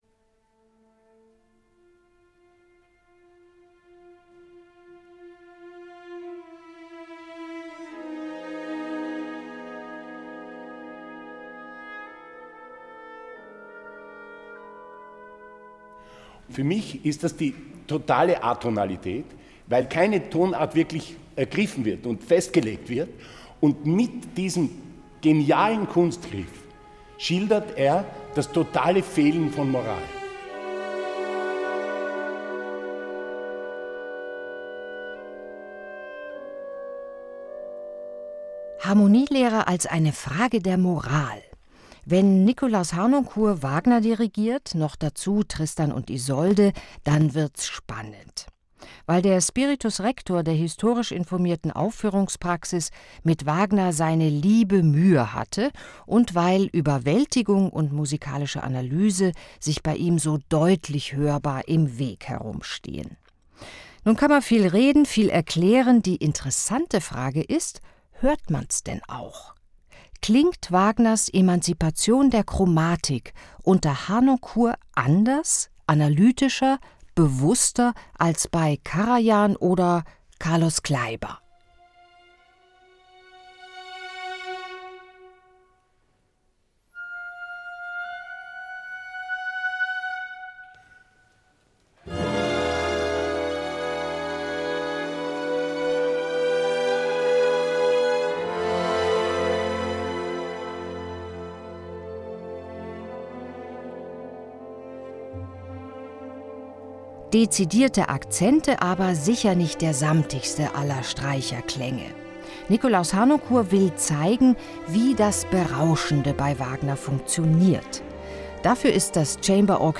Album-Tipp
Rechtzeitig zum 10. Todestag des Dirigenten erscheint nun der bislang unveröffentlichte Mitschnitt dieses Konzerts.
Dezidierte Akzente, aber sicher nicht der samtigste aller Streicherklänge: Nikolaus Harnoncourt will zeigen, wie das Narkotische bei Wagner funktioniert.